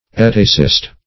Etacist \E"ta*cist\, n. One who favors etacism.